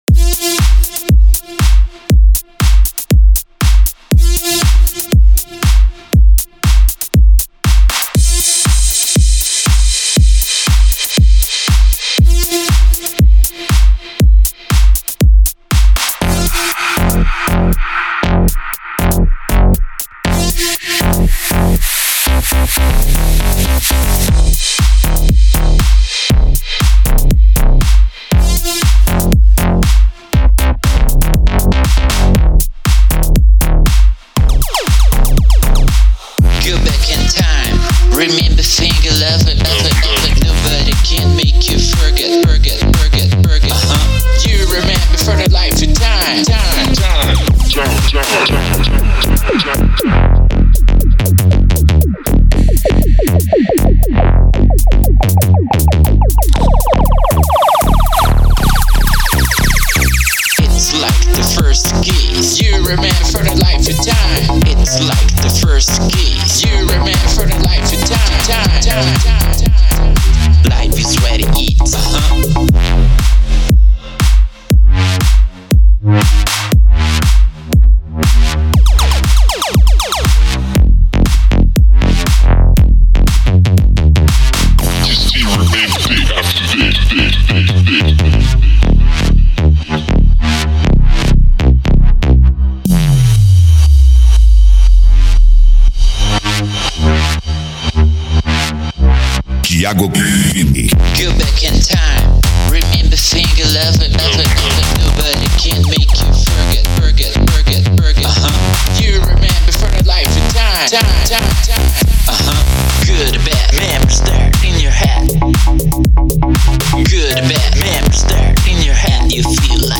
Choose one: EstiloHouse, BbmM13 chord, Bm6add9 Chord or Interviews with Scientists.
EstiloHouse